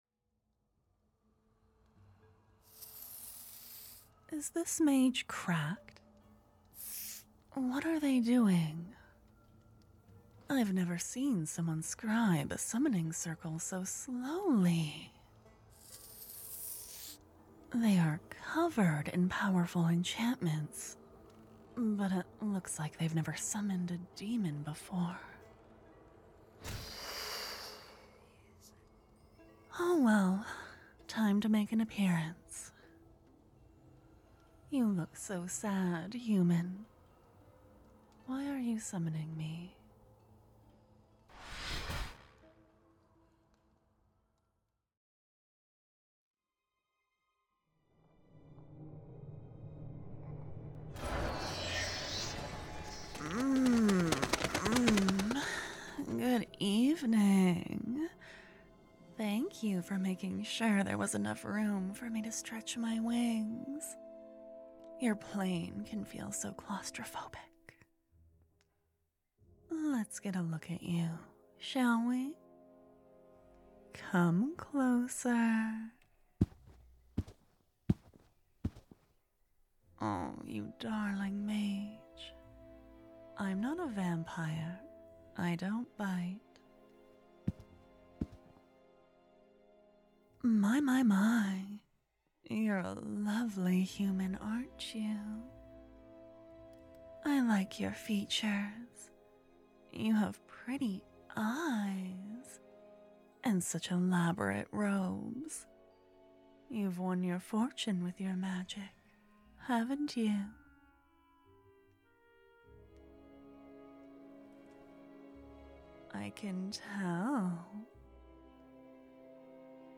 There's a rapidity to it that I really struggled with, I kept wanting to add pauses to let it breathe.